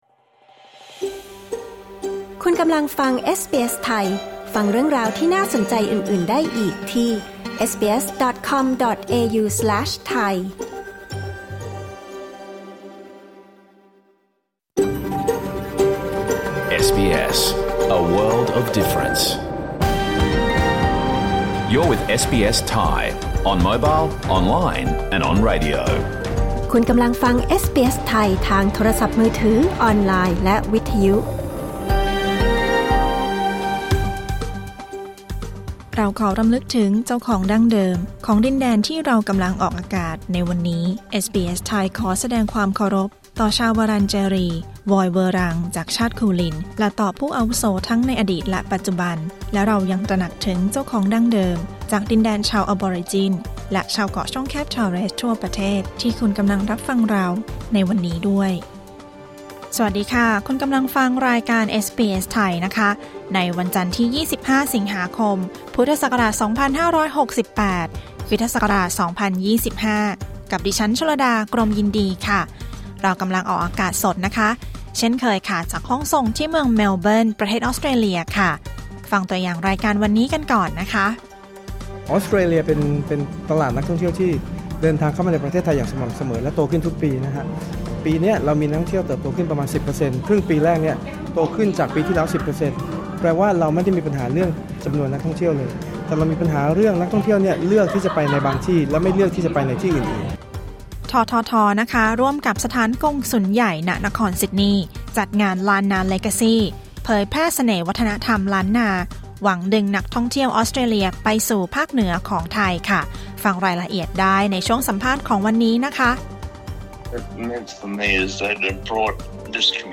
รายการสด 25 สิงหาคม 2568